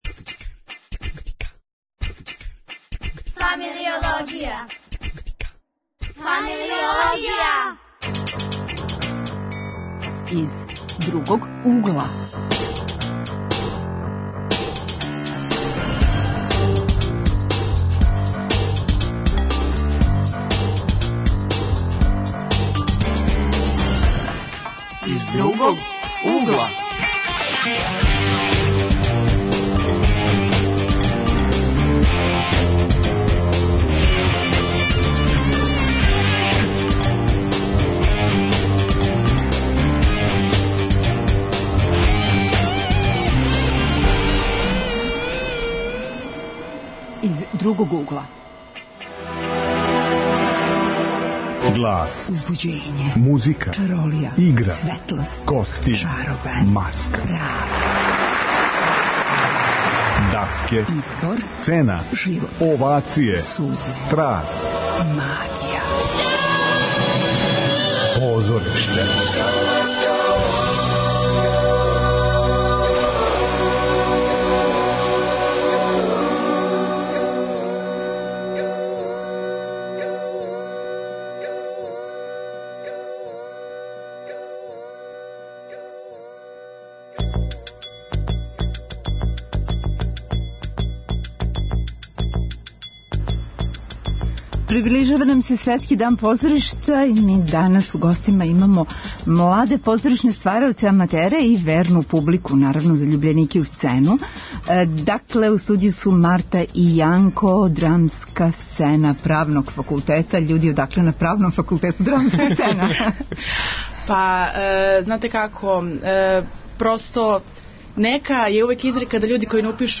Гости - студенти Драмске групе Правног факултета и средњошколци.